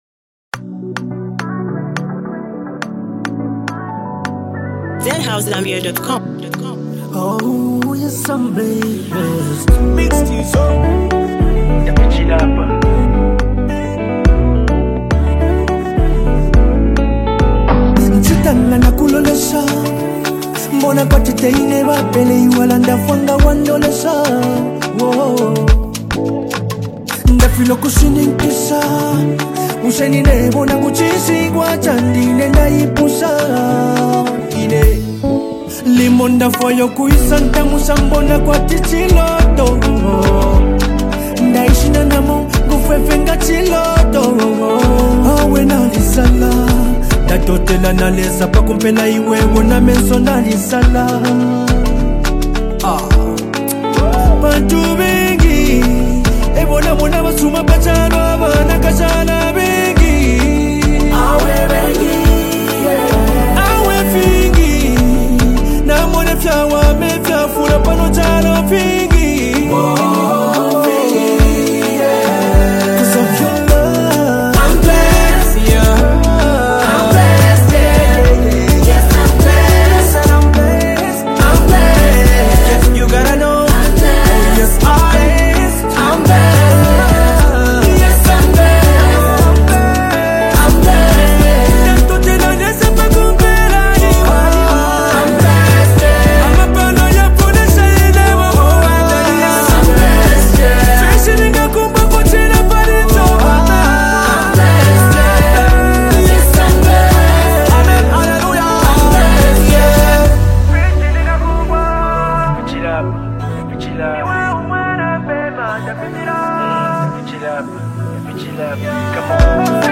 uplifting vibes
ZambianMusic